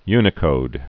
(ynĭ-kōd)